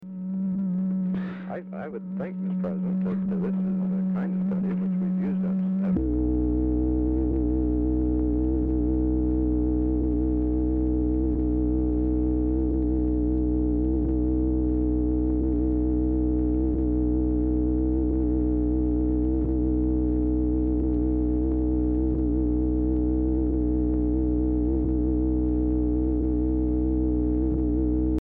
Oval Office or unknown location
"DIDN'T RECORD"; BRIEF PORTION OF CONVERSATION RECORDED
Telephone conversation
Dictation belt